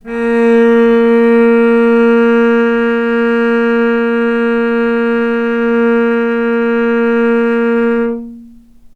vc-A#3-mf.AIF